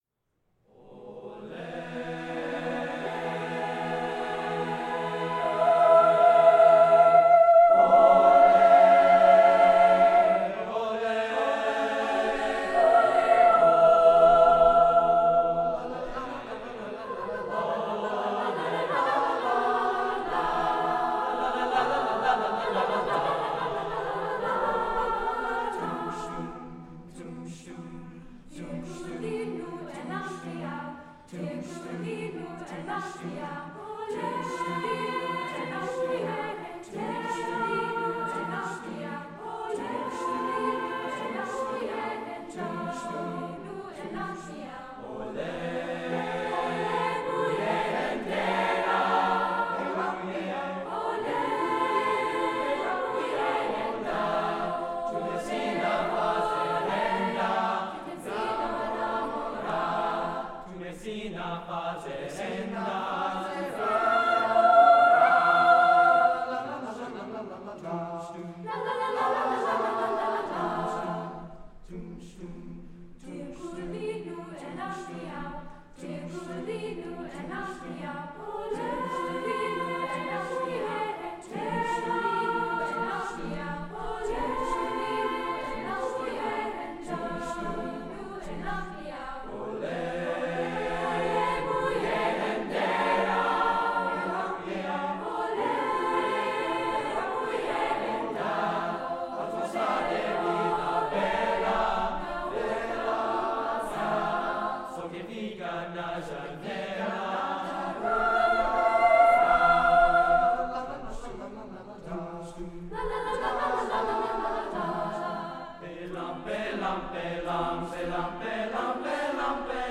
Brookline High School Spring Music Festival
Concert Choir